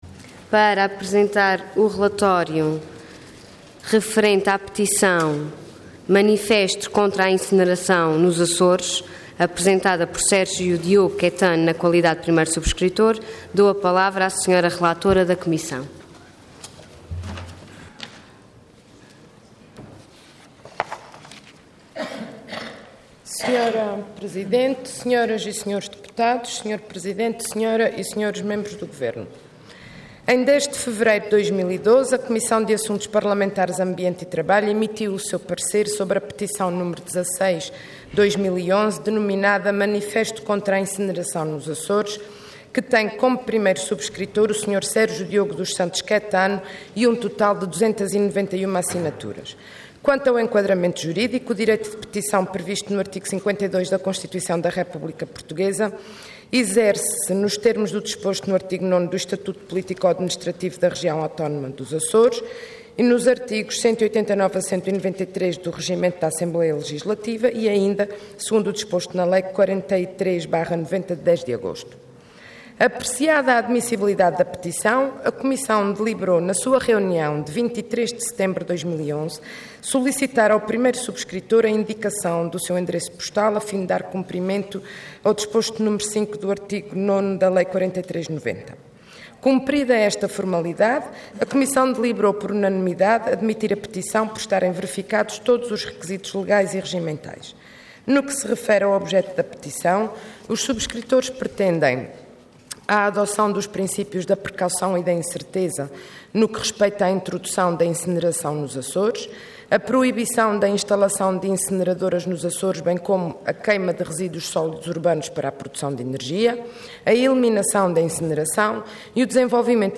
Intervenção Petição Orador Isabel Rodrigues Cargo Relatora Entidade Comissão de Assuntos Parlamentares Ambiente e Trabalho